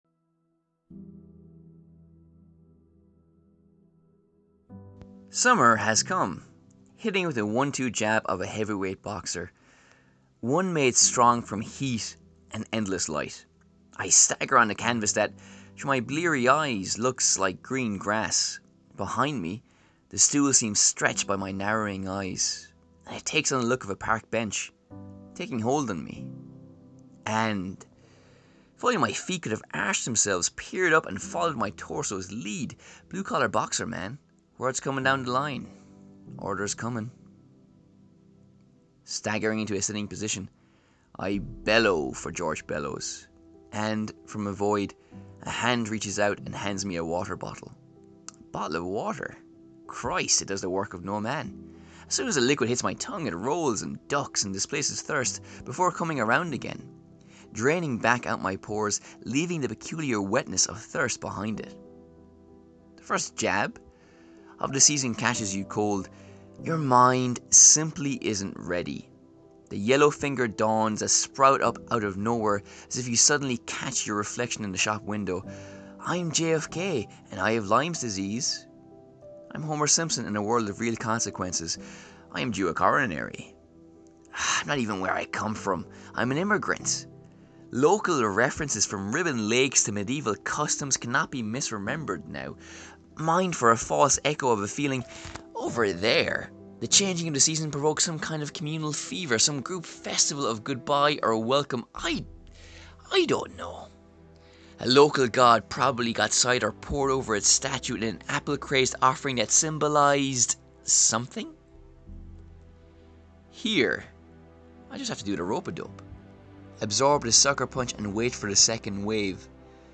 A short story in the latest edition of the long-running series. Taking its cue from the style of Laurie Lee, and other wide-ranging influences, this story is about change.